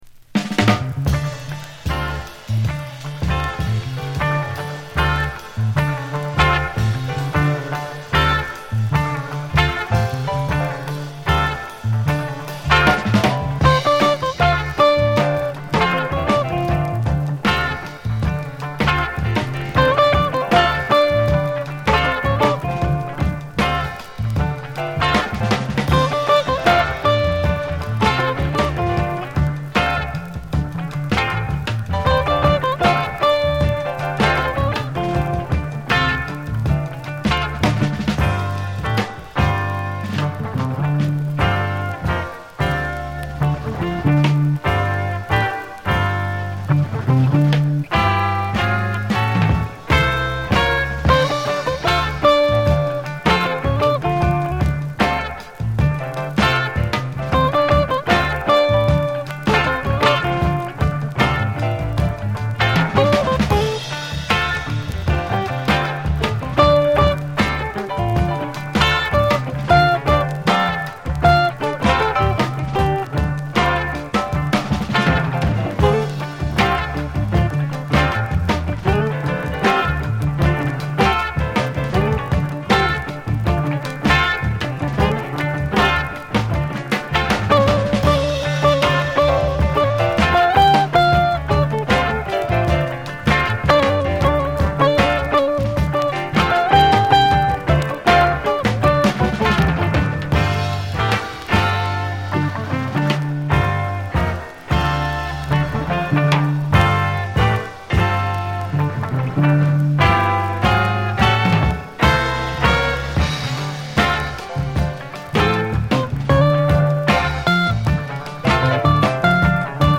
重厚でソウルフルな